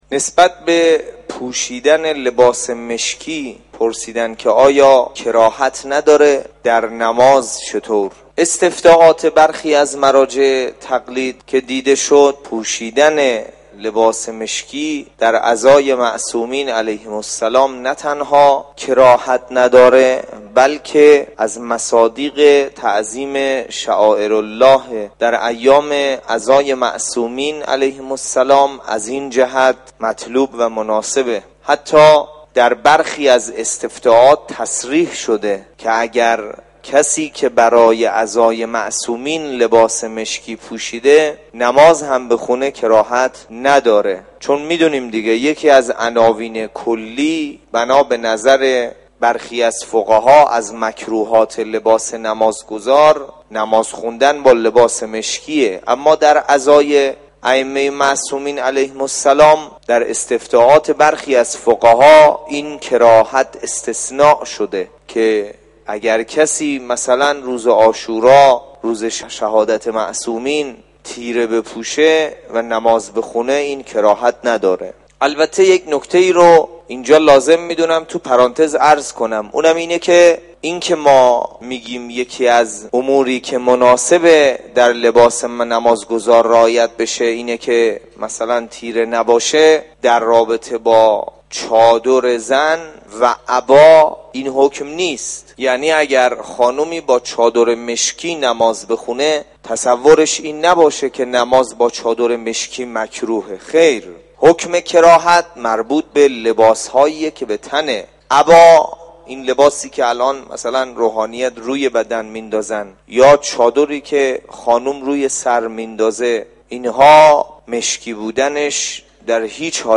پاسخ توسط استاد